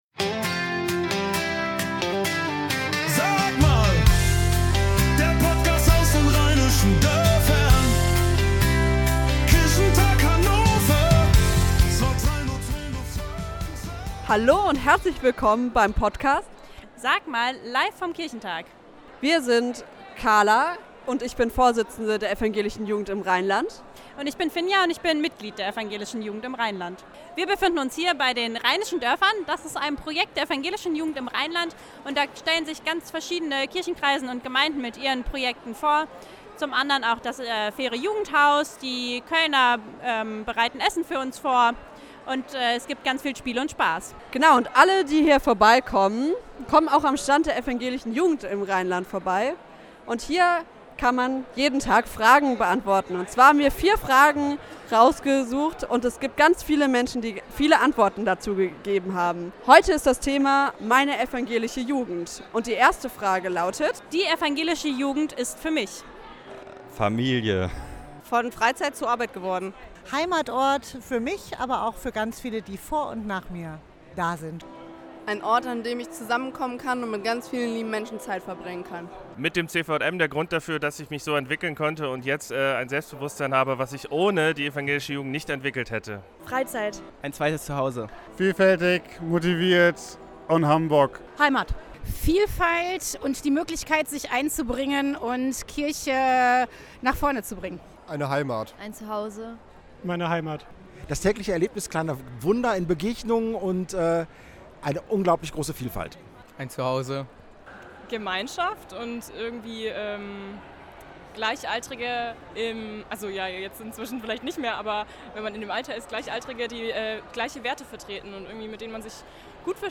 Der Podcast aus den Rheinischen Dörfern der EjiR vom Kirchentag Hannover